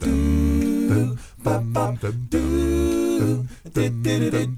ACCAPELLA 4.wav